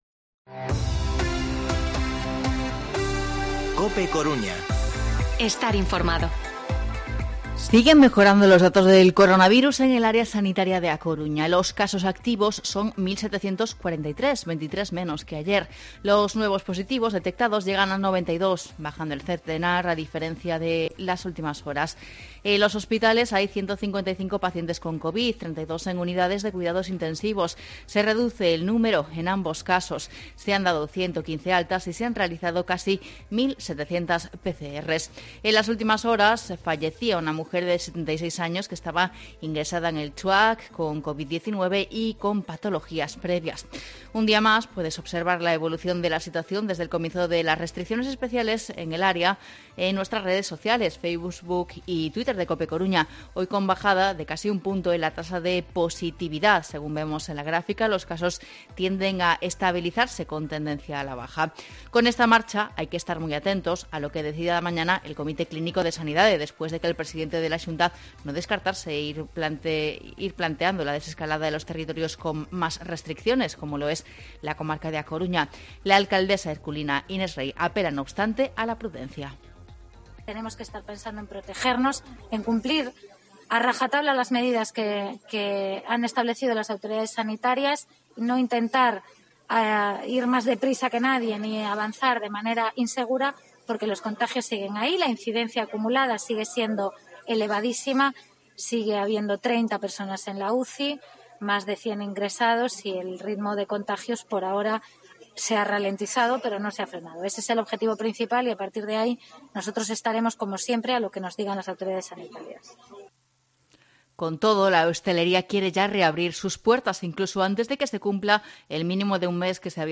Informativo mediodía COPE Coruña 26/11/2020 De 14:20 a 14:30 horas